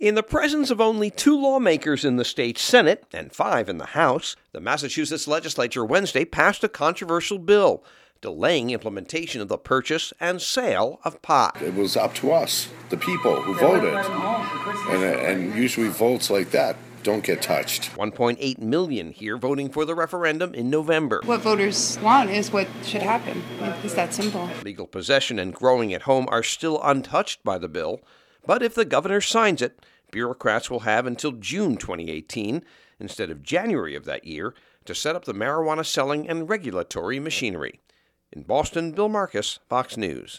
REPORTS FROM BOSTON.